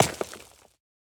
Minecraft Version Minecraft Version 1.21.5 Latest Release | Latest Snapshot 1.21.5 / assets / minecraft / sounds / block / pointed_dripstone / land5.ogg Compare With Compare With Latest Release | Latest Snapshot